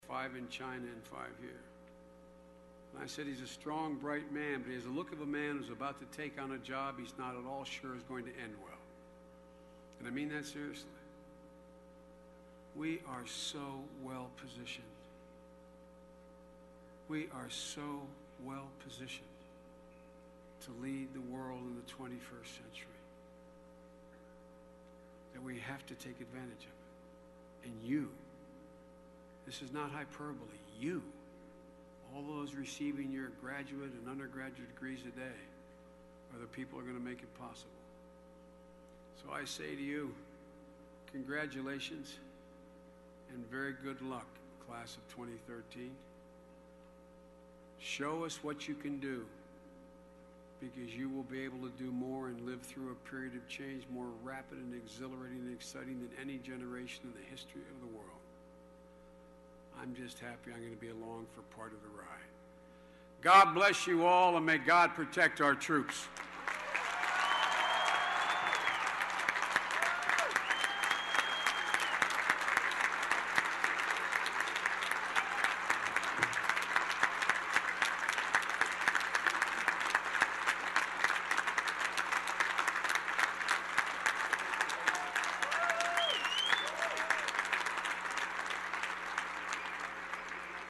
公众人物毕业演讲第416期:拜登2013宾夕法尼亚大学(14) 听力文件下载—在线英语听力室